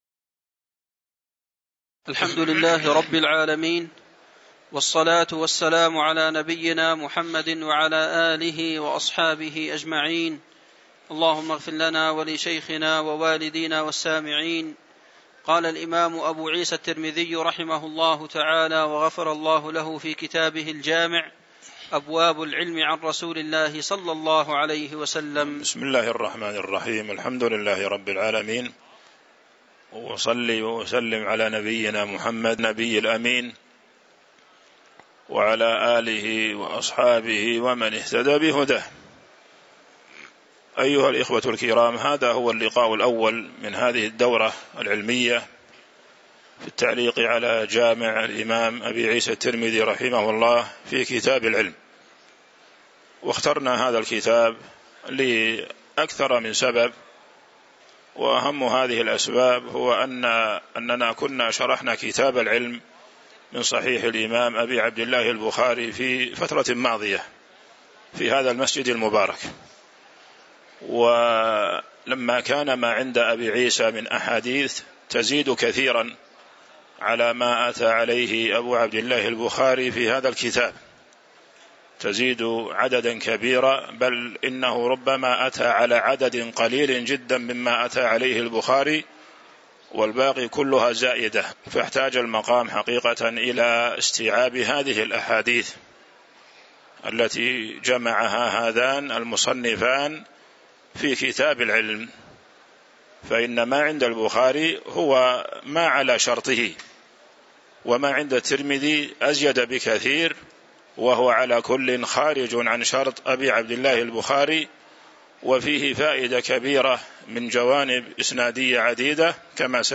تاريخ النشر ٢٤ شوال ١٤٤٤ هـ المكان: المسجد النبوي الشيخ